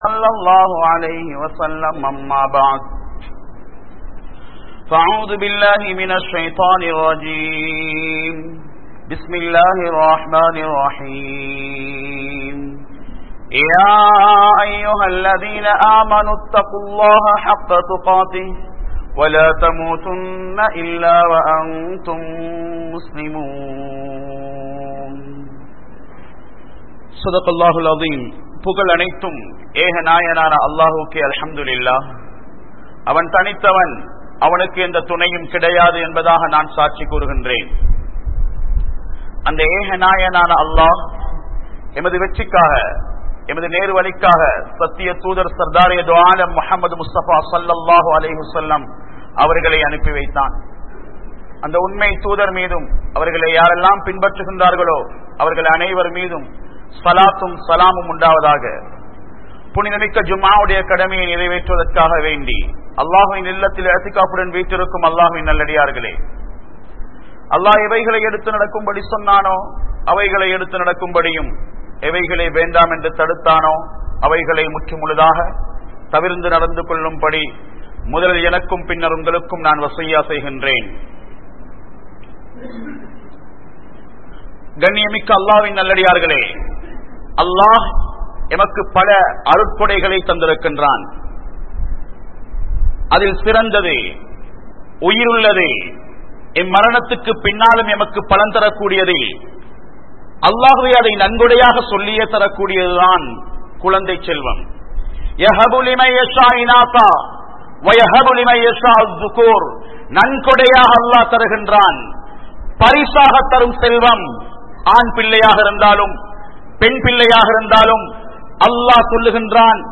Petroarhal Varaium Oavium Pillaihal (பெற்றோர்கள் வரையும் ஓவியம் பிள்ளைகள்) | Audio Bayans | All Ceylon Muslim Youth Community | Addalaichenai
Mathala, Town Jumua Masjith